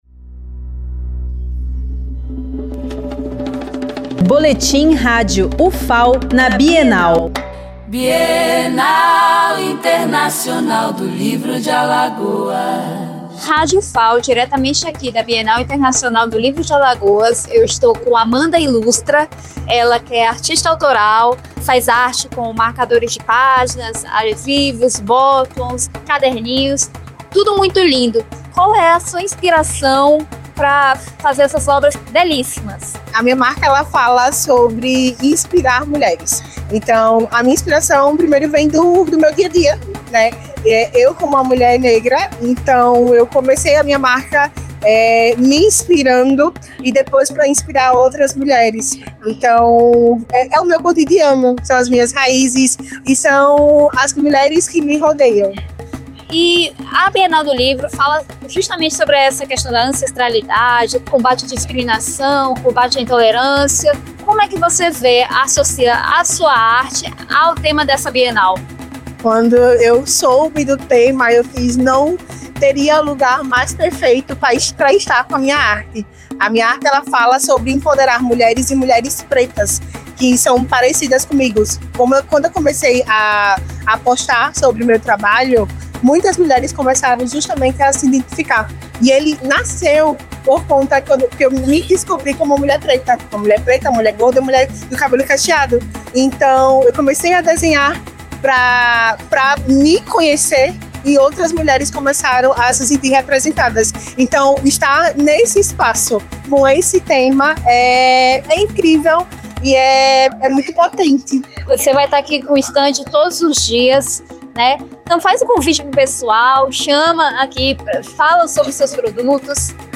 Entrevista realizada na 11ª Bienal Internacional do Livro de Alagoas, de 31 de outubro a 9 de novembro de 2025